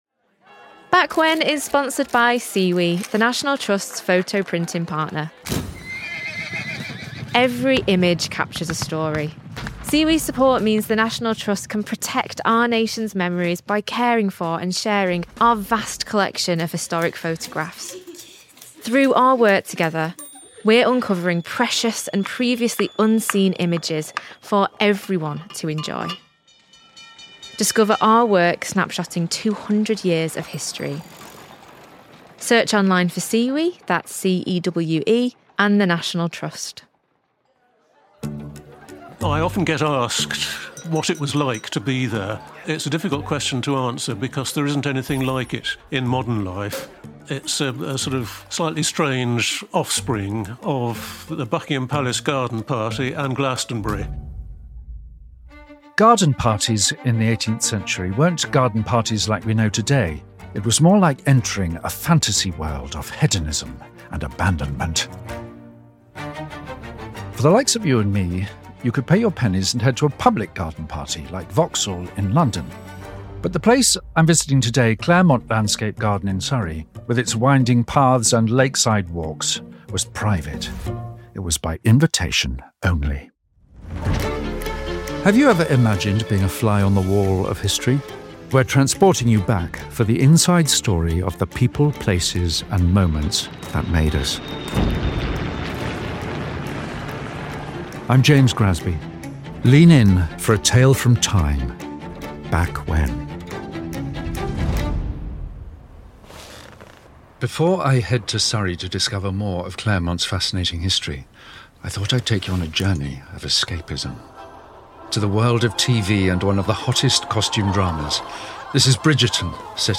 Join a tour of a garden designed for pleasure, as we relive its party heyday.